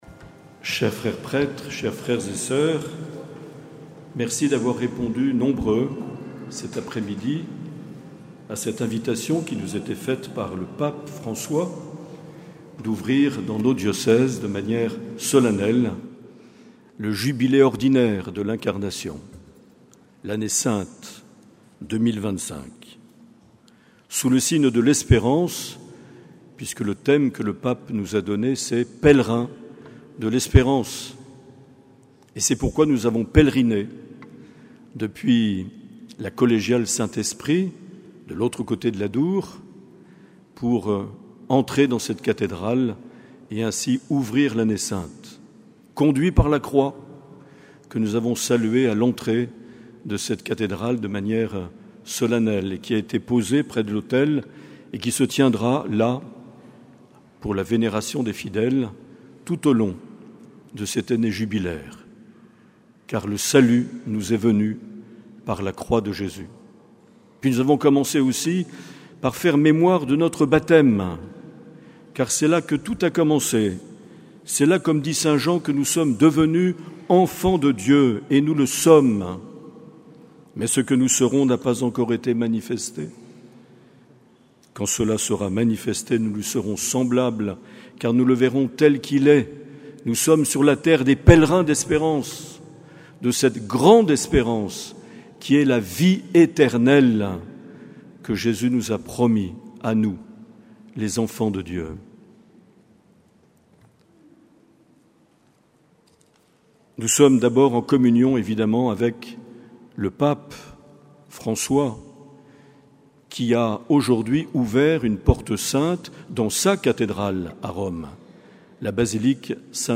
29 décembre 2024 - Ouverture de l'Année Sainte à la cathédrale de Bayonne
Homélie de Mgr Marc Aillet.